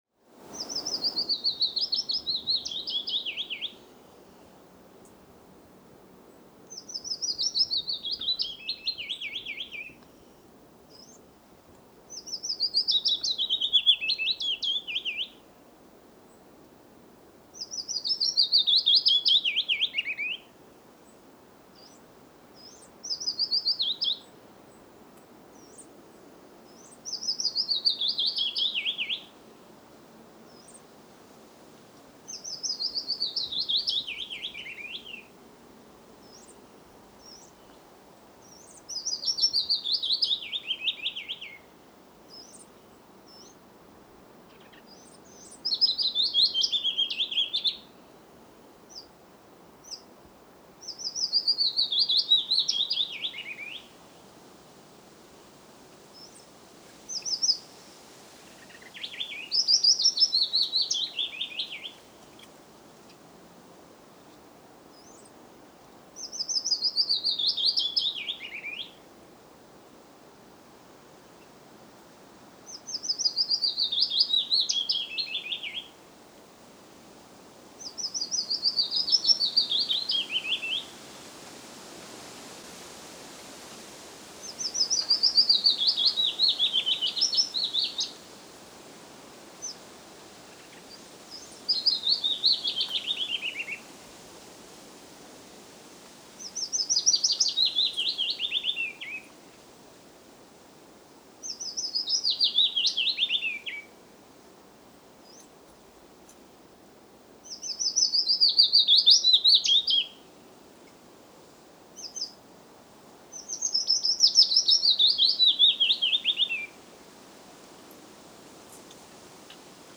CALLS AND SONGS
The Willow Warbler’s typical call is a soft “hoo-eet” with rising inflection. The song is a series of sweet, rather melancholy “swee” notes increasing gradually in volume before descending in long, terminal flourish.
PHYLLOSCOPUS-TROCHILUS-.mp3